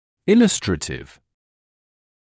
[‘ɪləstrətɪv][‘иластрэтив]иллюстративный, снабженный иллюстрациями; пояснительный